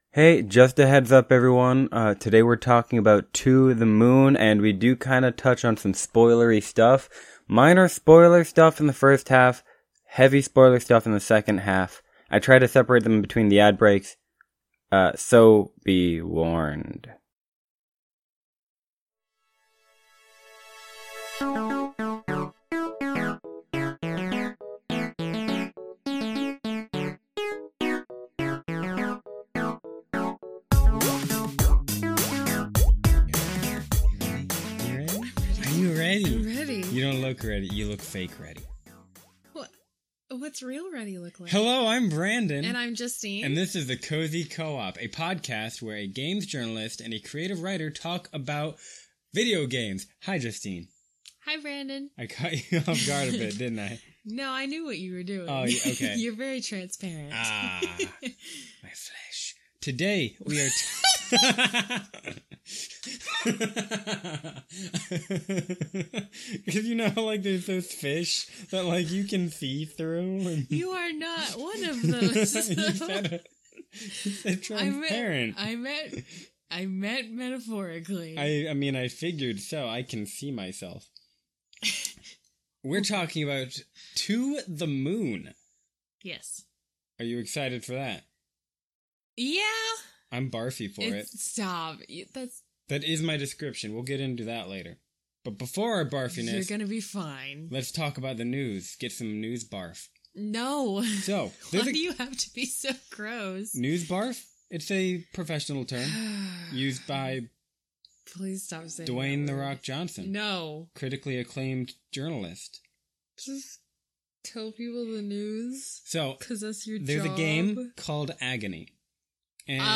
The Cozy Co-op is a podcast where a games journalist and a creative writer talk about video games!